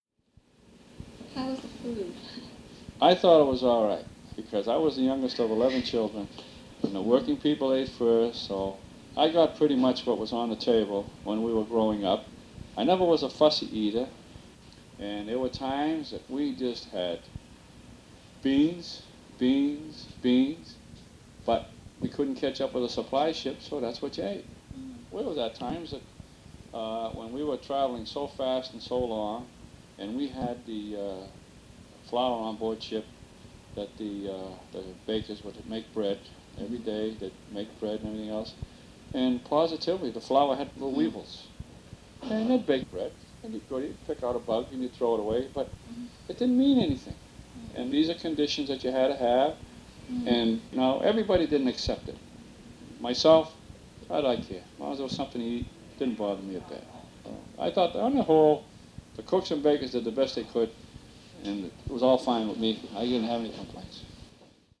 USS CASSIN YOUNG , Oral history